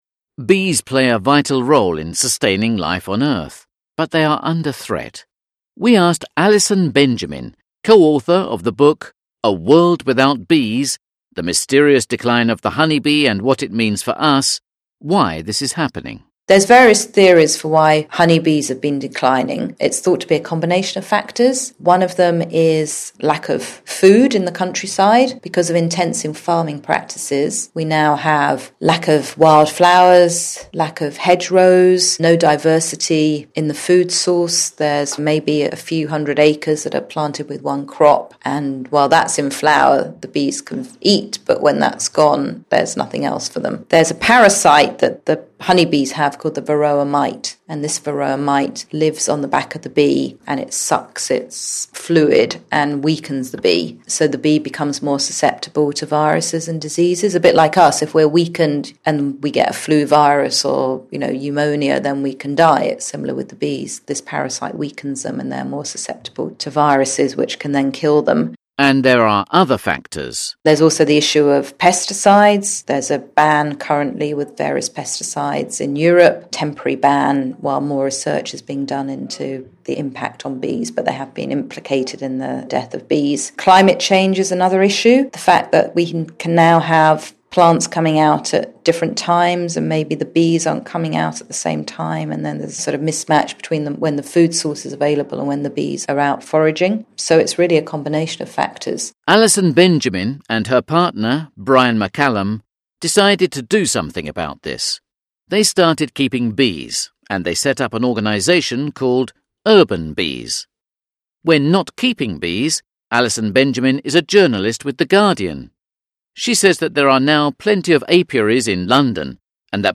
Interview: Honey, I’m home!